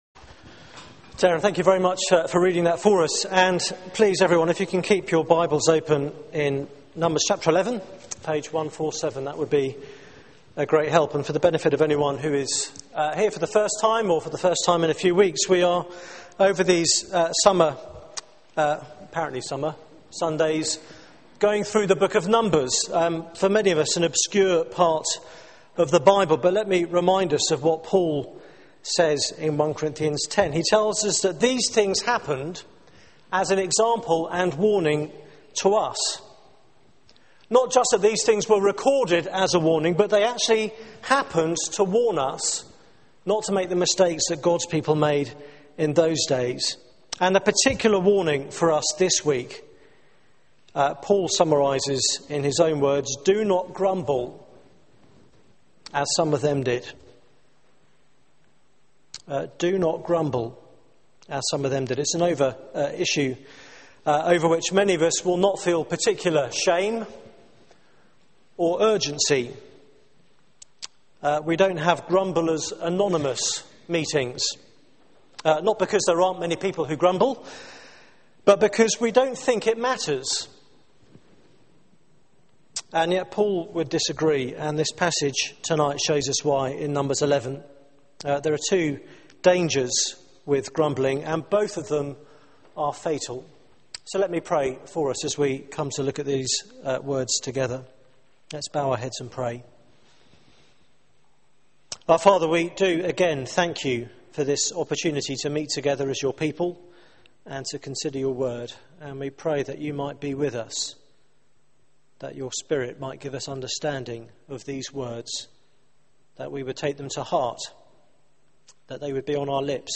Media for 6:30pm Service on Sun 03rd Jun 2012 18:30
Series: In Transit : The Book of Numbers Theme: The danger of grumbling Sermon